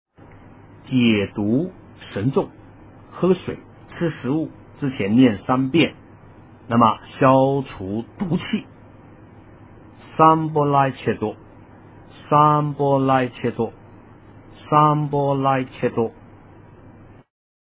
诵经
佛音 诵经 佛教音乐 返回列表 上一篇： 佛 下一篇： 解怨咒 相关文章 南无阿弥陀佛--佛音 南无阿弥陀佛--佛音...